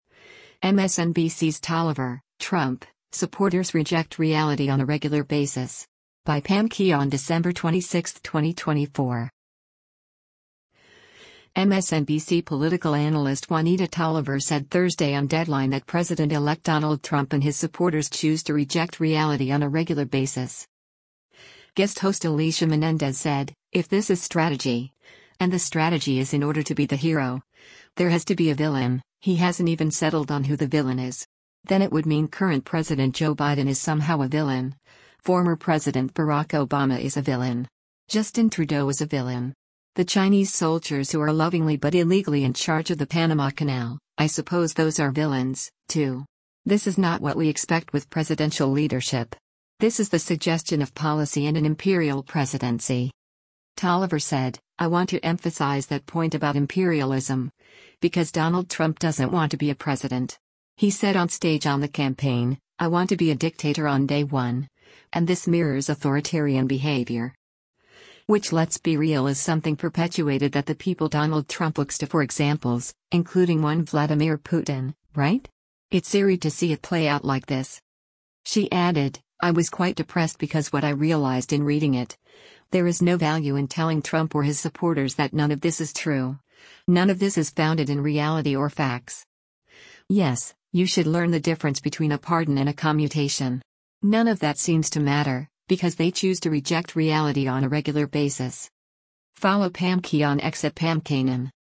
MSNBC political analyst
Guest host Alicia Menendez said, “If this is strategy, and the strategy is in order to be the hero, there has to be a villain, he hasn’t even settled on who the villain is.